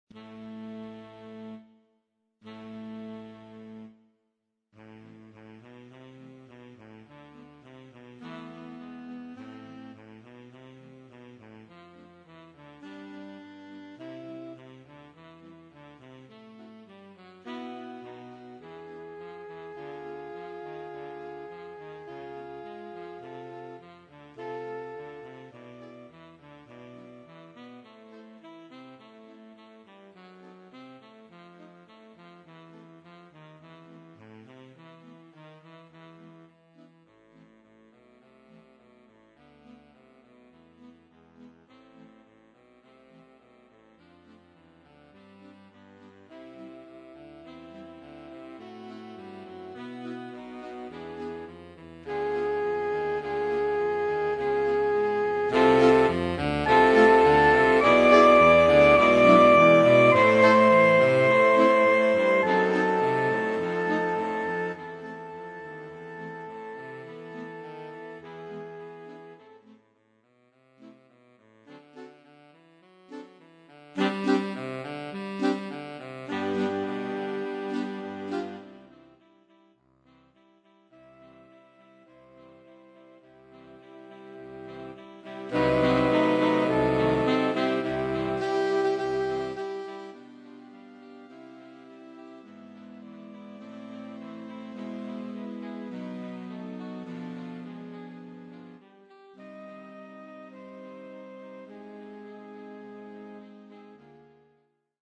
für Saxophonchor
Instrumentalnoten für Saxophon